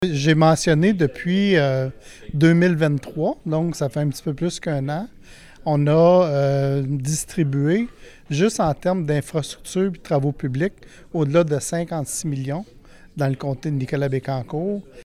L’annonce a été faite en conférence de presse, jeudi après-midi.
Le député de Nicolet-Bécancour, Donald Martel, en a profité pour rappeler le montant des sommes consenties depuis 2023 dans les petites municipalités rurales de son comté.